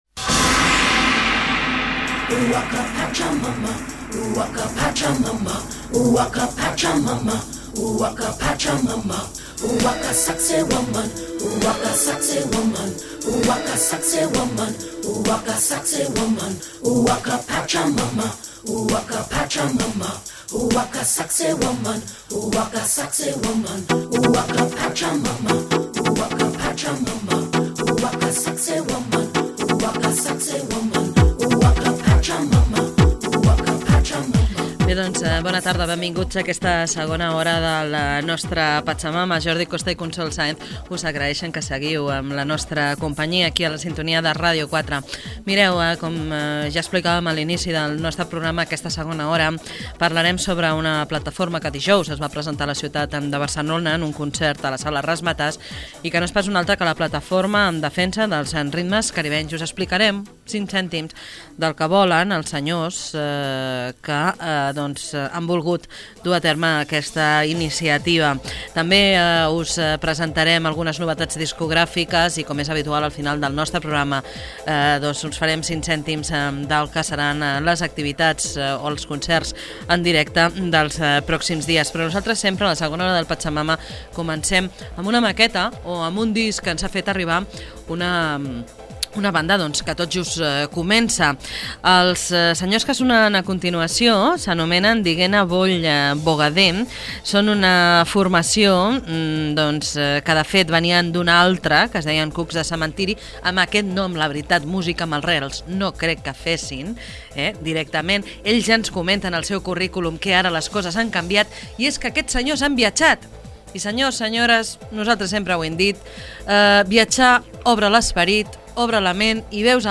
Ssintonia del programa, presentació de la segona hora dedicada a la plataforma en defensa dels ritmes caribenys, tema musical
Musical